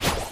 fang_dryfire_01.ogg